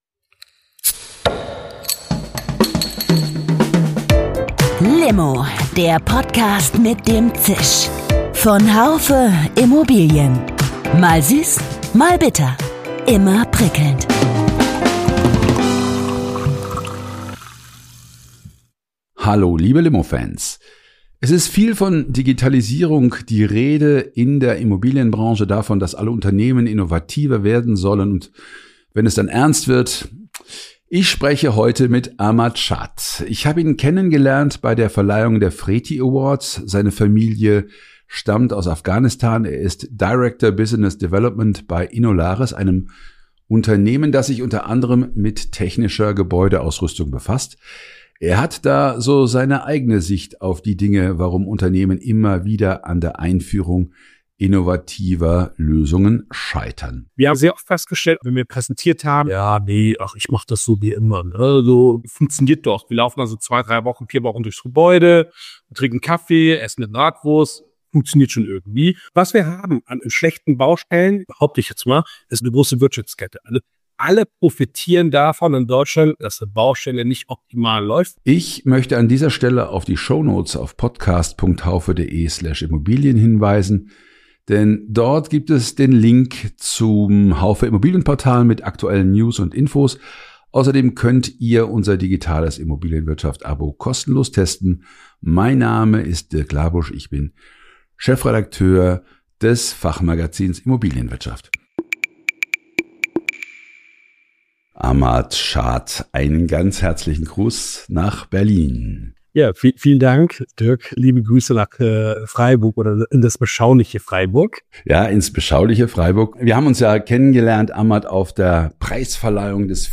Ein Gespräch über Kommunikation und über die Grenzen der Veränderungsbereitschaft. Das Thema digitale Brandschutzprüfung ist nur ein Symbol für die Unlust zu Veränderungen in anderen Bereichen.